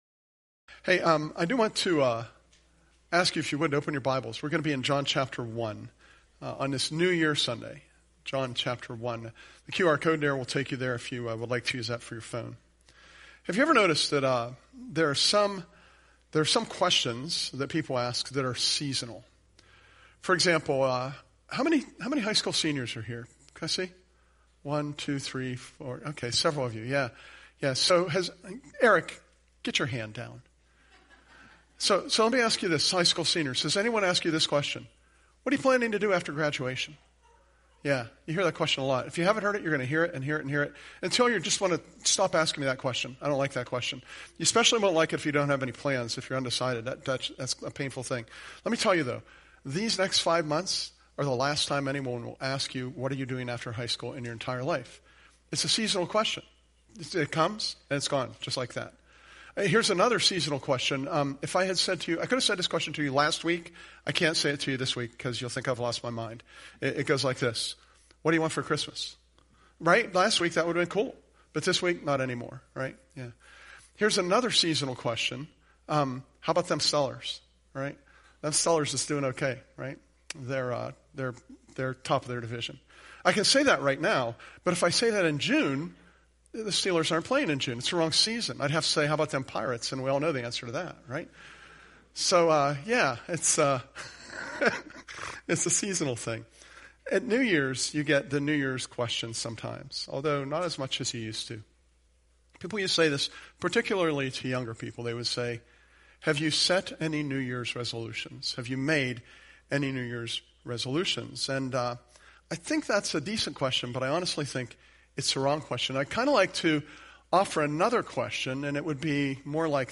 Presented at Curwensville Alliance